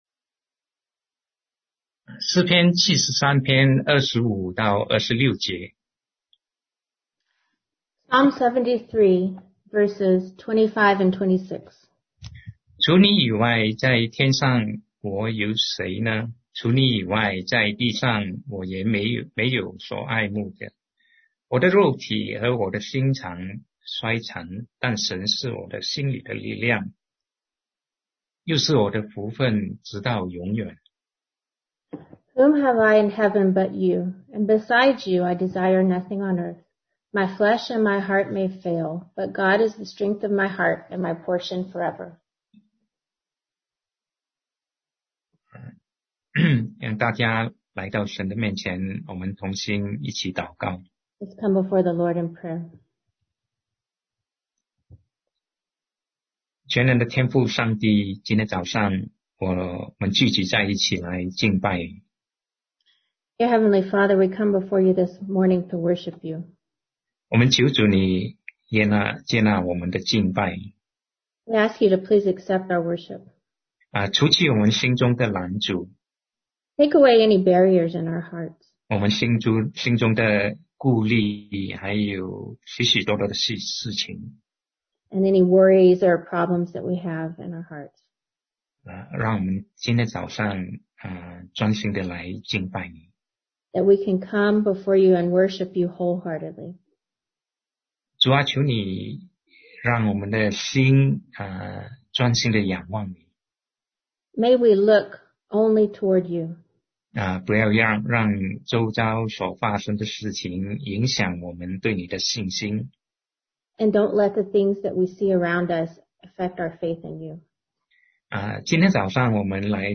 Sermon 2020-10-25 Reasons for Not Being Fearful